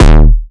Index of /m8-backup/M8/Samples/breaks/breakcore/earthquake kicks 2
nutz kick.wav